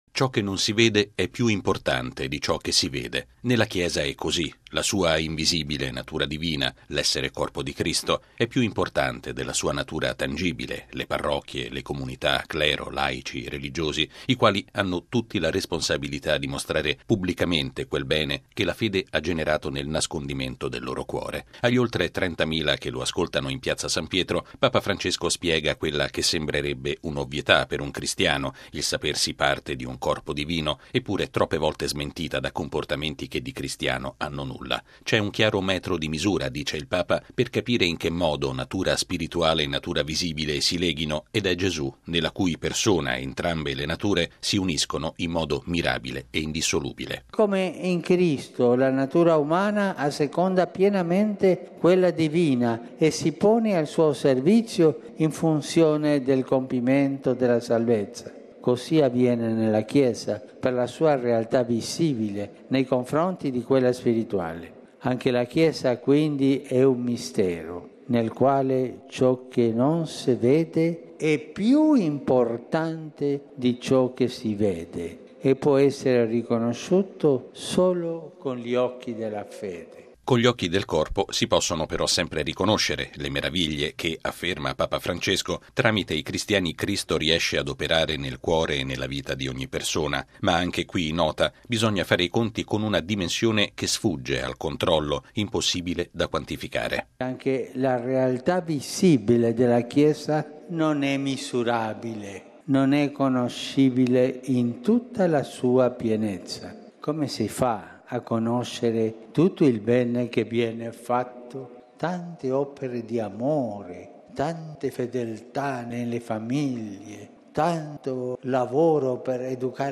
Lo ha ribadito Papa Francesco all’udienza generale in Piazza San Pietro, dedicata al rapporto tra realtà “visibile” e realtà “spirituale” che convivono nella natura della Chiesa. Il Papa ha poi pregato per gli studenti rapiti a fine settembre nello Stato Messicano di Guerrero.